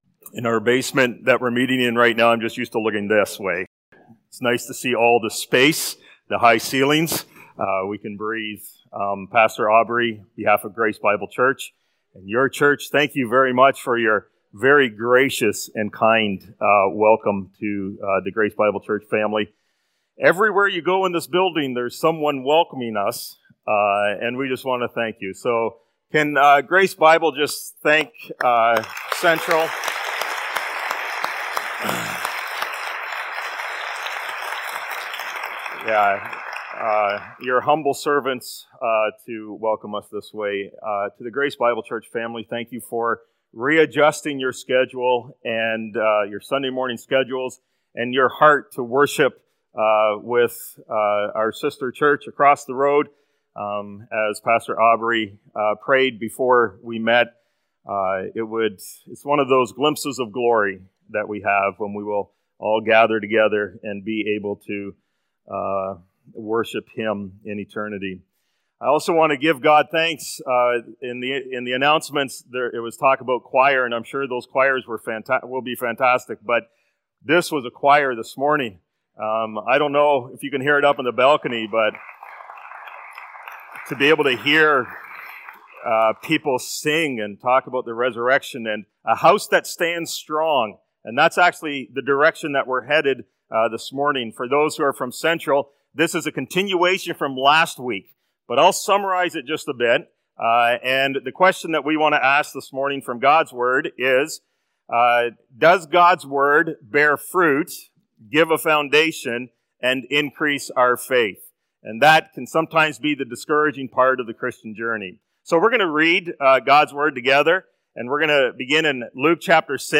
March-30-Sermon.mp3